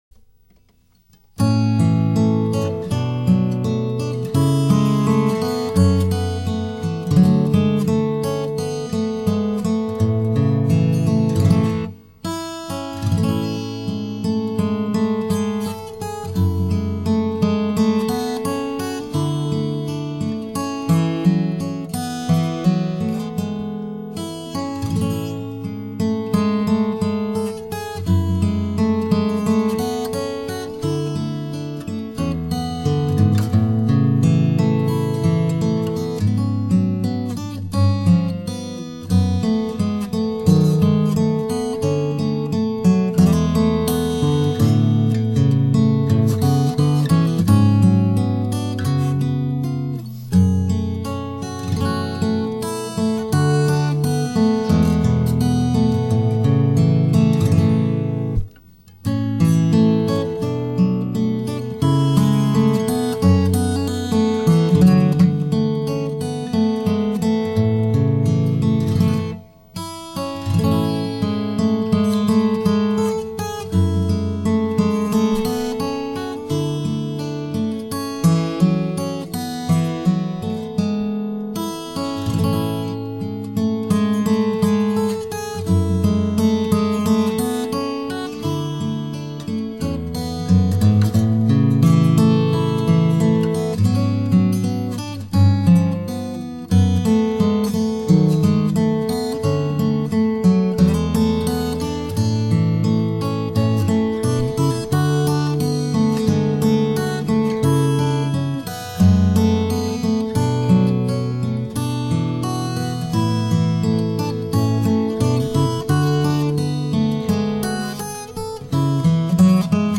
モデル名 Santacruz OM Model Custom 1994年製
トップ材 シトカスプルース
音色に関しては、遠鳴りはしません、いわゆる傍鳴りですが官能的です。マイクで拾った音色は格別です。
Sound of Santacruz OM　Recorded by RODE NT1-A